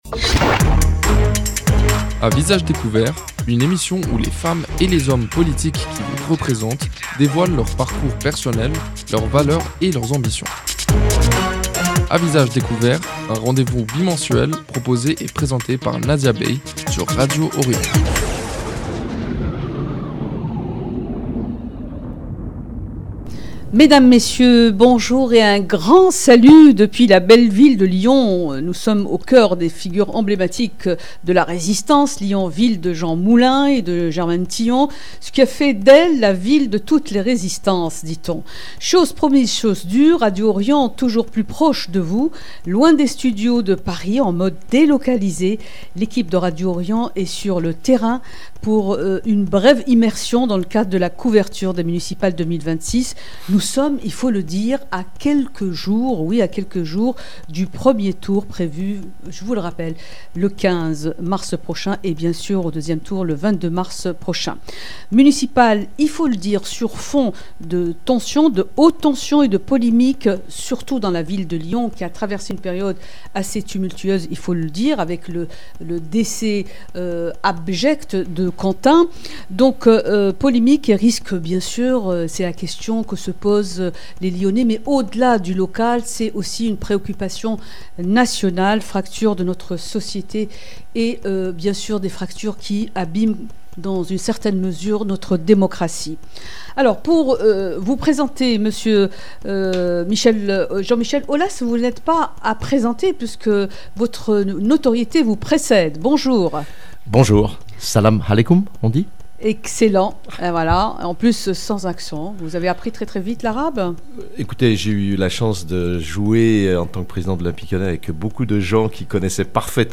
Invité de l’émission À Visage Découvert, spéciale Municipales 2026, Jean-Michel Aulas , candidat à la mairie de Lyon avec la liste « Cœur Lyonnais », présente les grandes lignes de son programme. Il met notamment l’accent sur le renforcement de l’attractivité économique de la ville, l’innovation et le soutien au tissu entrepreneurial afin de consolider le rayonnement de Lyon. 0:00 29 min 55 sec